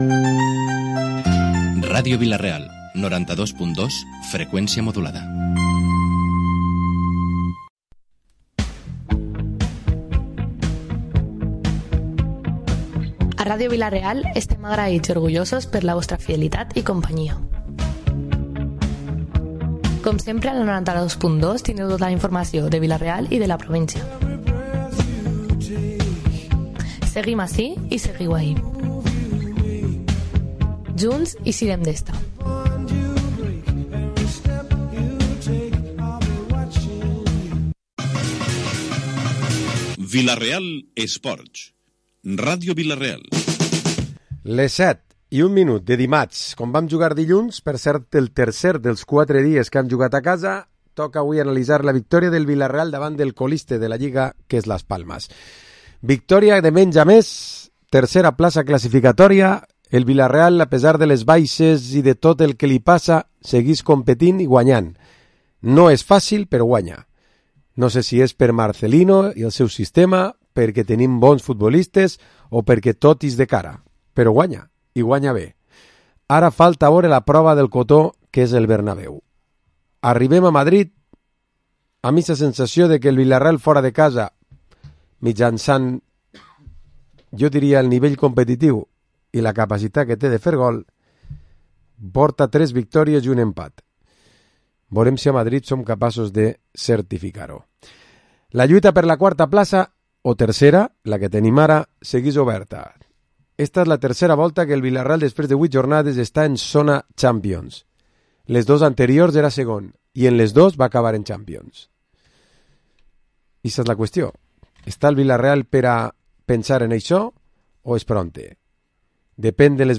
Programa esports tertúlia dimarts 1 d’octubre